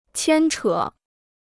牵扯 (qiān chě) Free Chinese Dictionary